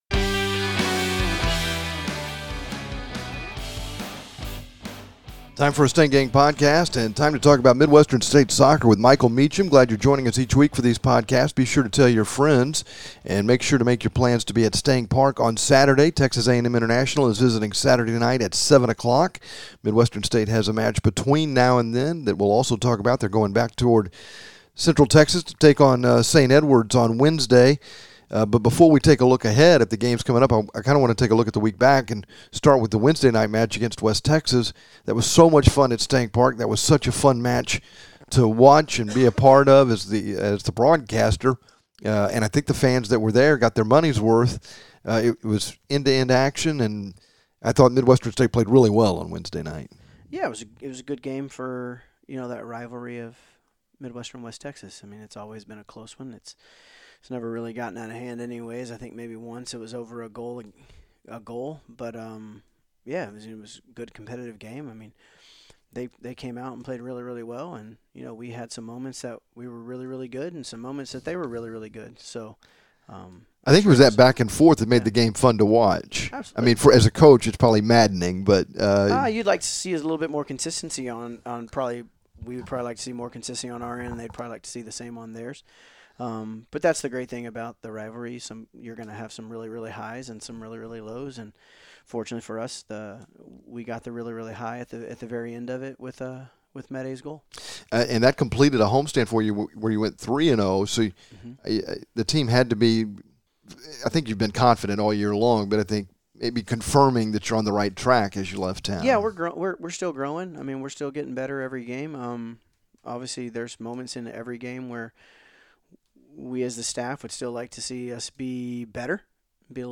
This week's Stang Gang Podcast features complete coverage of Midwestern State Men's Soccer and an interview